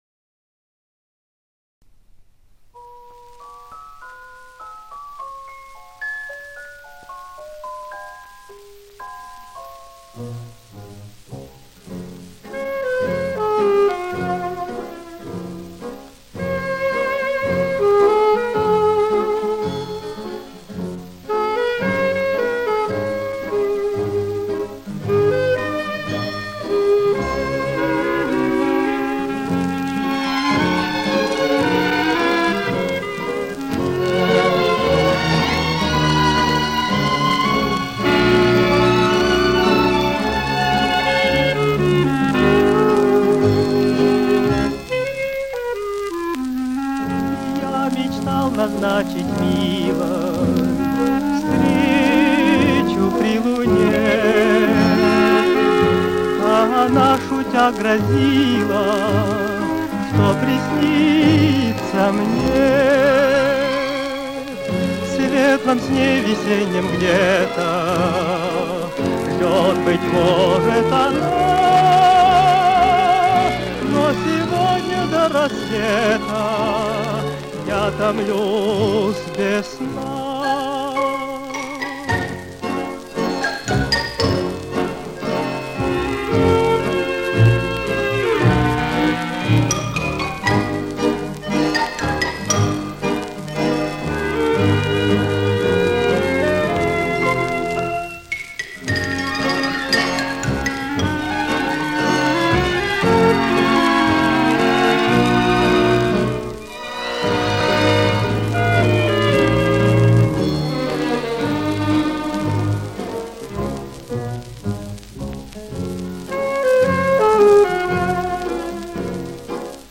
слоу фокс.